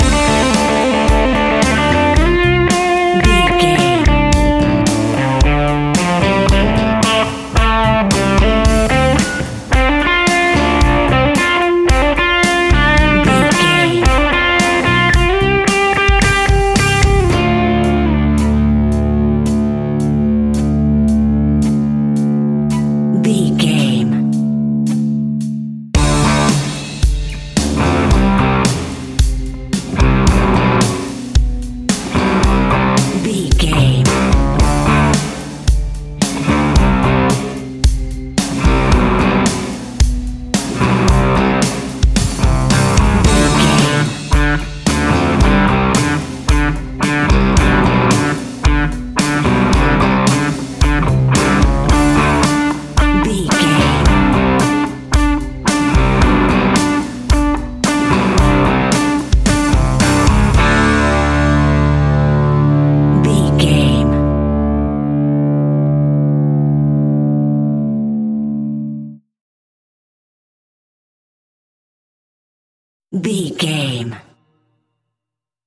Ionian/Major
D
energetic
driving
aggressive
electric guitar
bass guitar
drums
hard rock
heavy metal
blues rock
heavy drums
distorted guitars
hammond organ